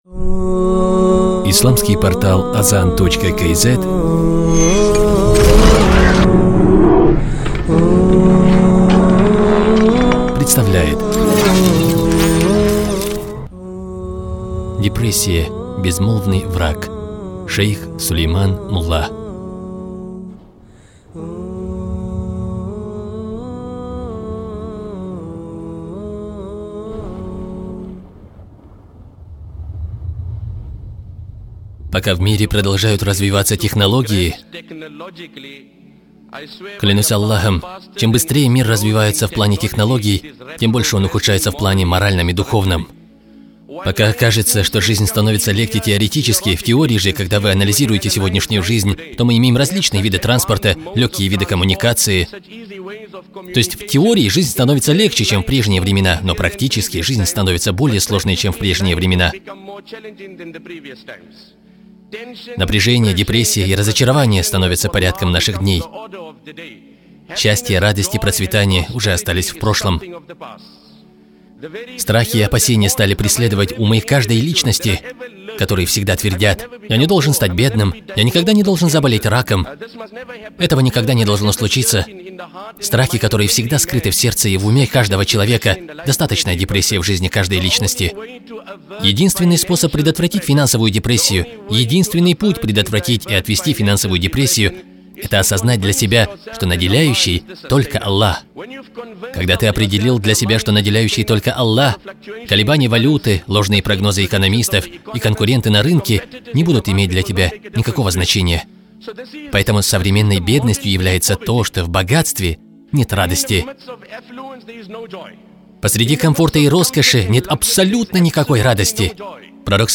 Лектор